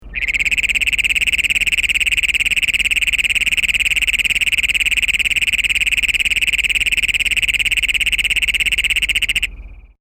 The call of the Great Plains Toad is an explosive jackhammer-like metallic trill lasting from 5 seconds to almost a minute.
Sound This is a recording of one repetition of the advertisement call of a Great Plains Toad
recorded at night in Riverside County.